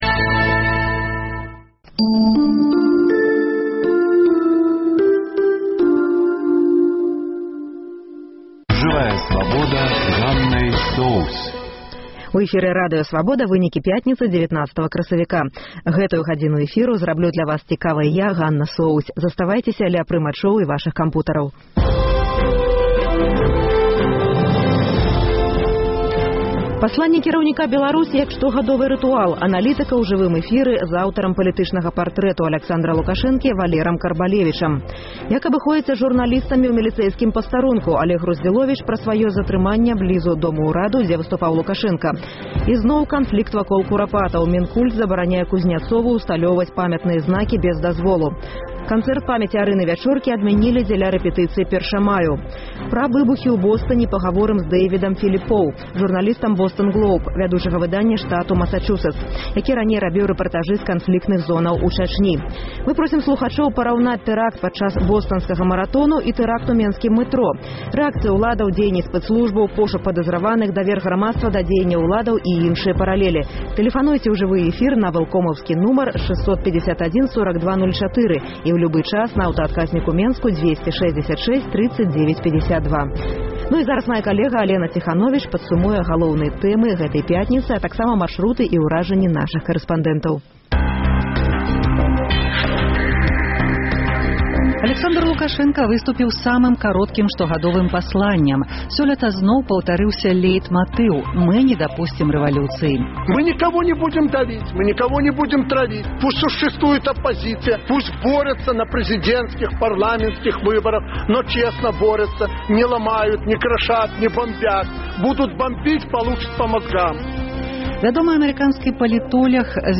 Мы просім слухачоў параўнаць тэракты падчас бостанскага маратону і ў менскім мэтро – рэакцыі ўладаў, дзеяньні спэцслужбаў, пошук падазраваных, давер грамадзтва да дзеяньняў уладаў і іншыя паралелі.